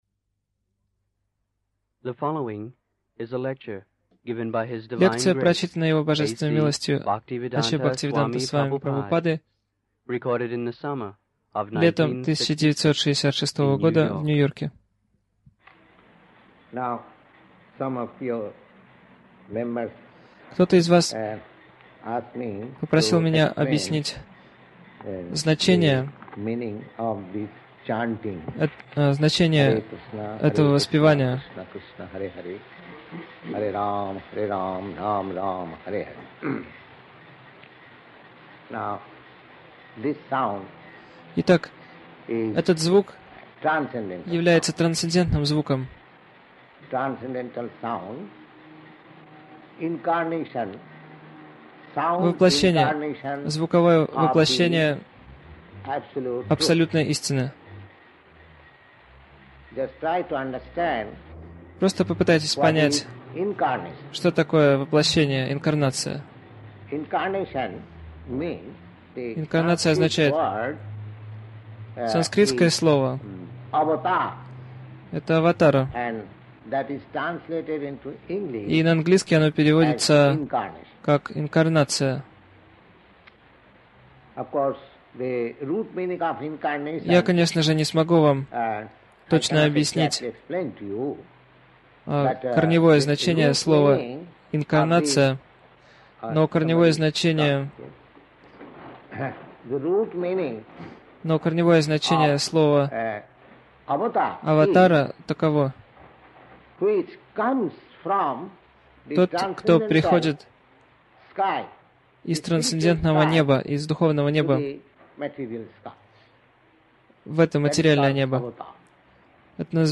Милость Прабхупады Аудиолекции и книги 30.08.1966 Разное | Нью-Йорк Харе Кришна мантра Загрузка...